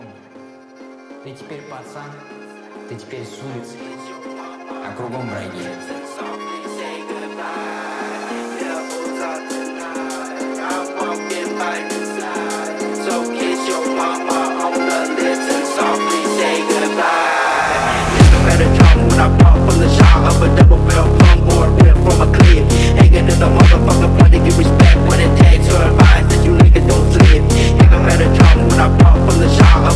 Жанр: Хаус / Русские